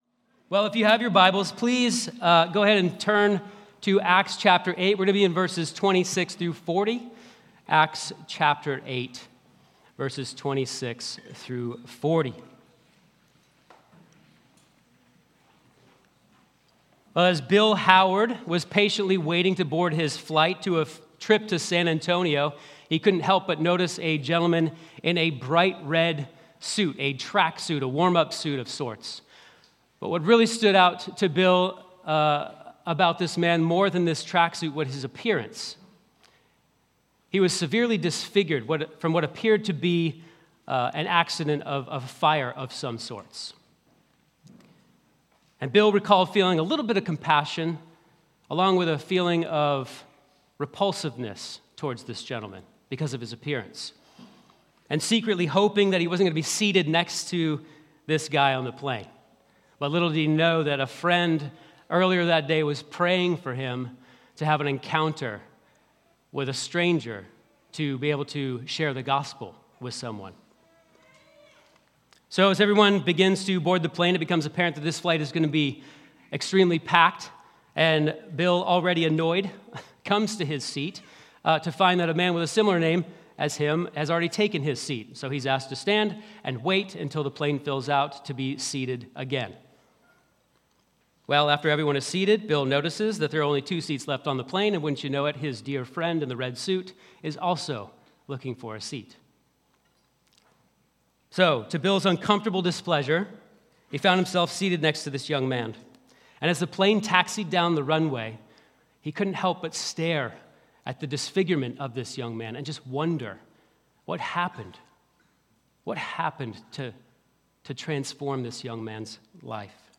Sermons | Risen Hope Church